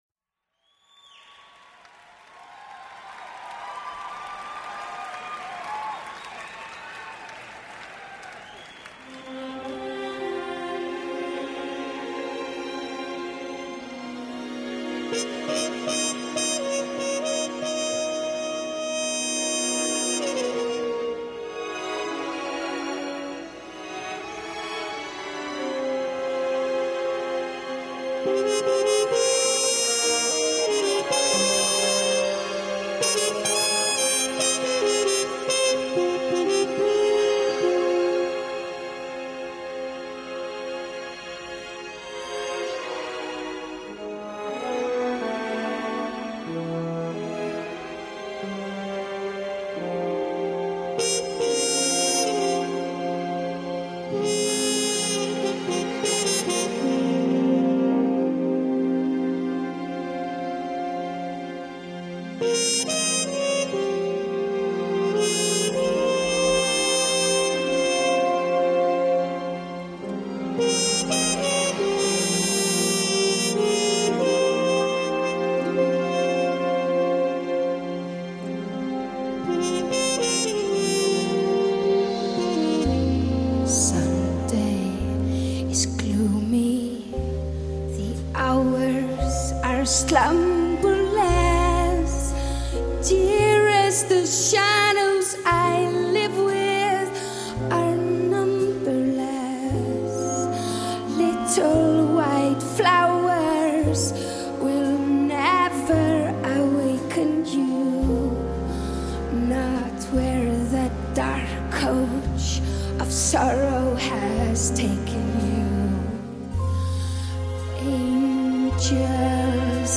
라이브곡입니다.